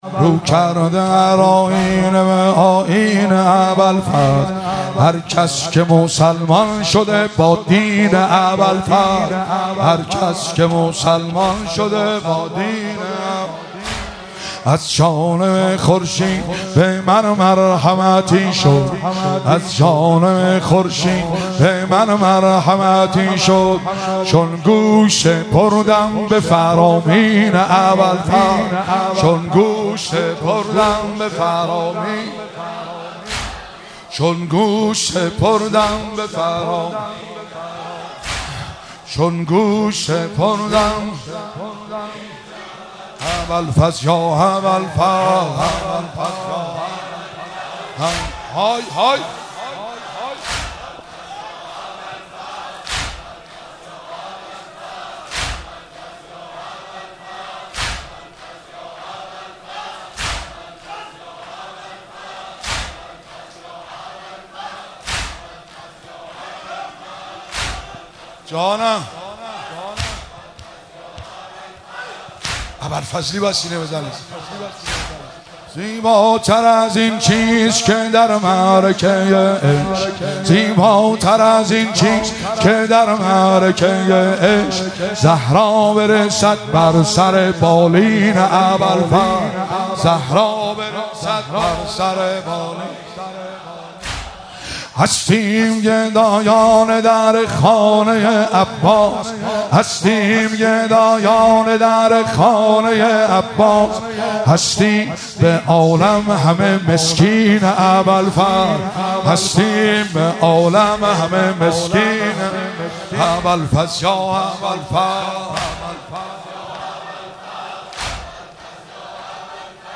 مراسم عزاداری شب تاسوعا محرم 1445